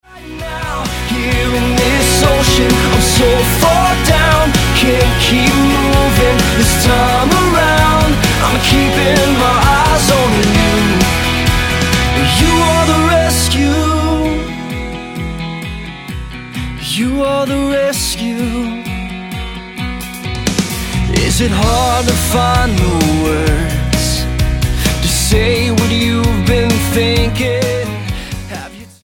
Purveyor of stylised pop rock or sincere music minister?
Style: Pop